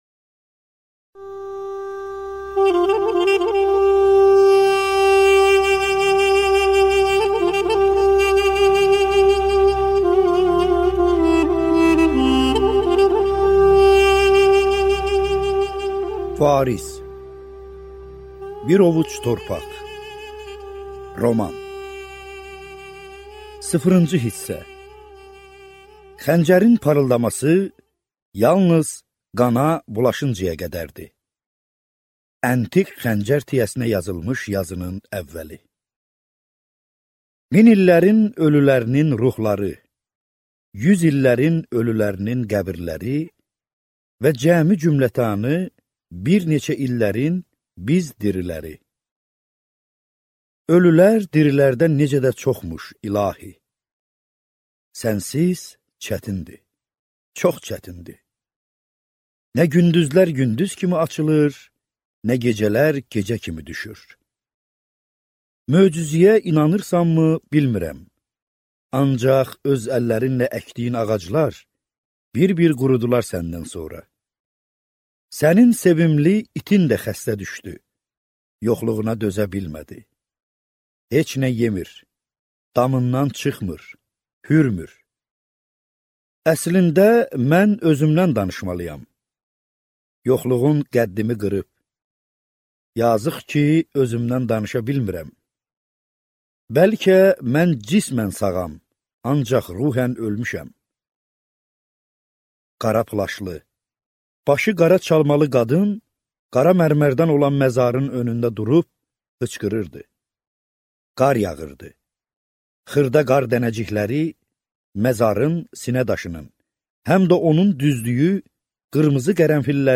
Аудиокнига Bir ovuc torpaq | Библиотека аудиокниг